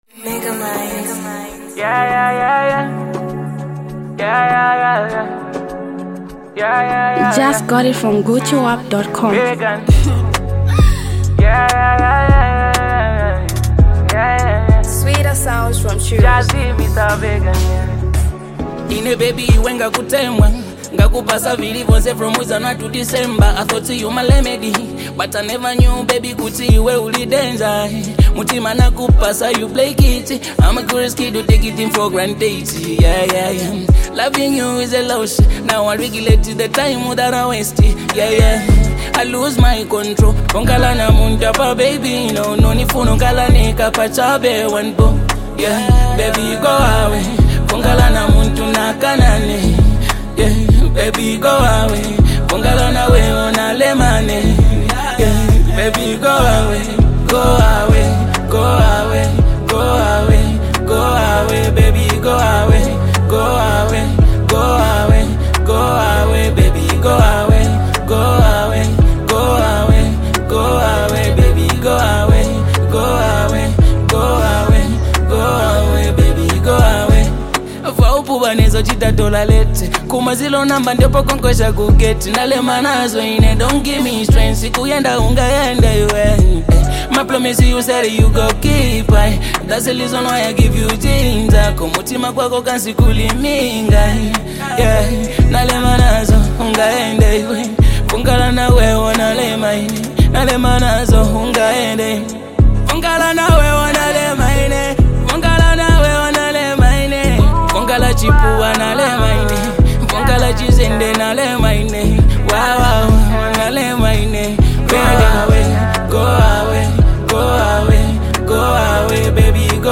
Zambian Mp3 Music
Afro-beat